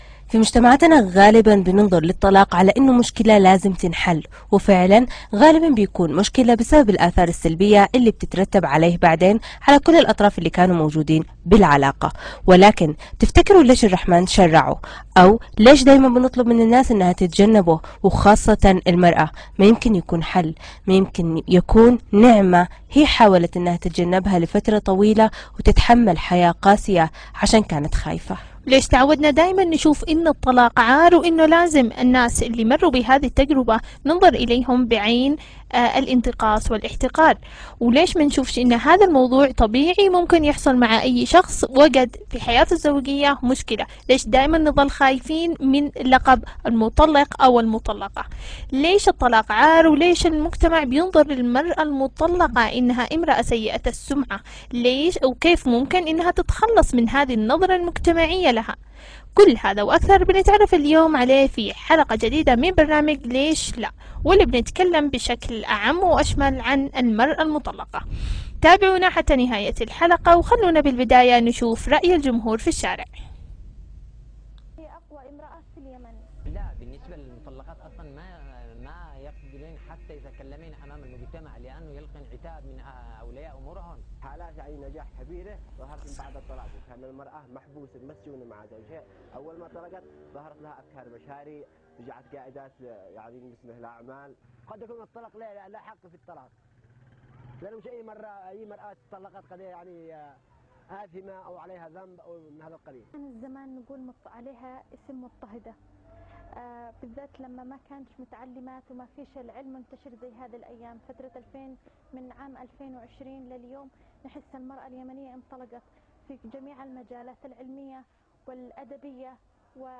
شاركونا آراءكم بتعليقاتكم في البث المباشر